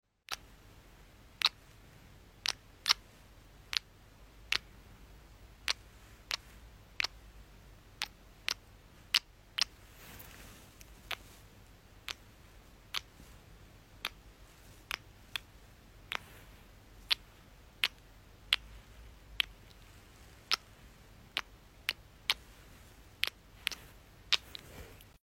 Kisses Asmr Just For You(🎥 Sound Effects Free Download